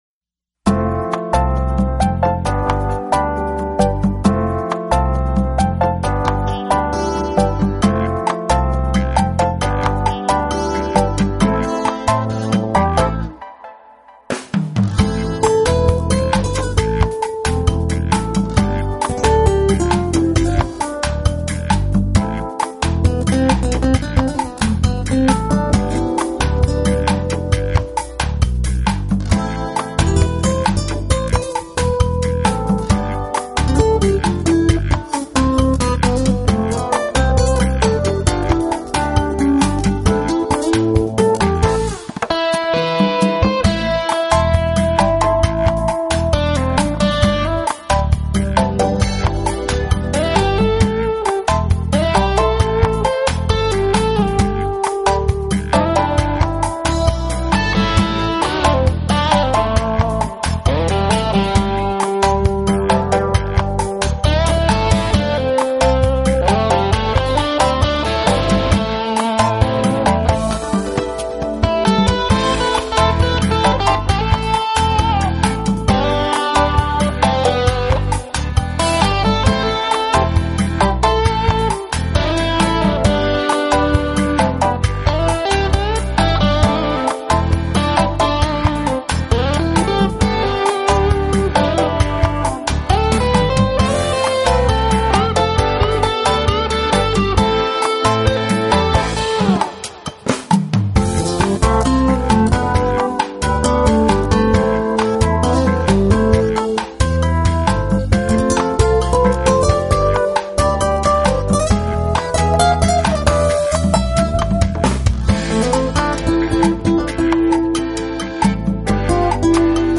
音乐流派: New Age / Smooth Jazz